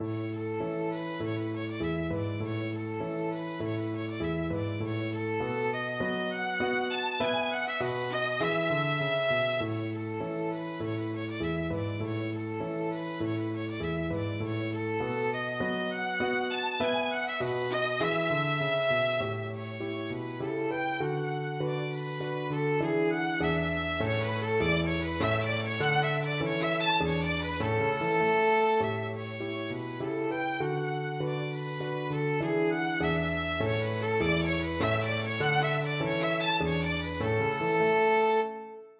Violin
A major (Sounding Pitch) (View more A major Music for Violin )
2/4 (View more 2/4 Music)
Moderato
Traditional (View more Traditional Violin Music)